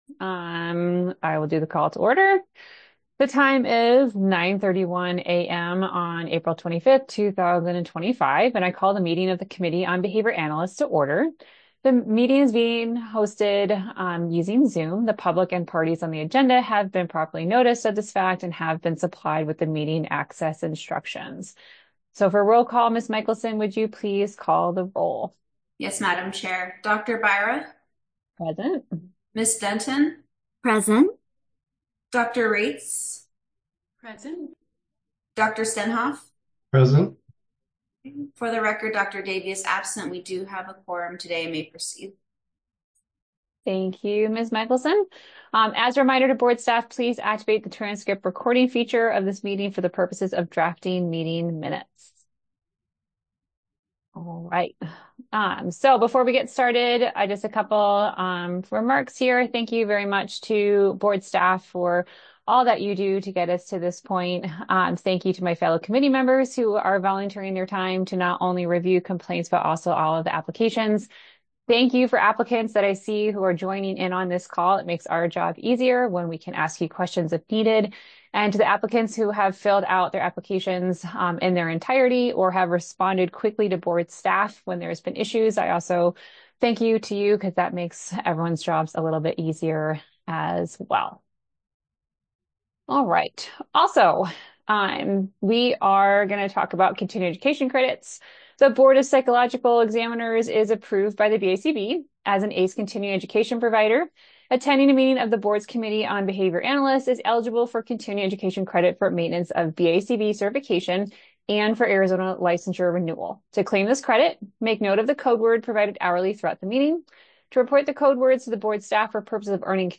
Committee on Behavior Analysts Meeting | Board of Psychologist Examiners
Members will participate via Zoom.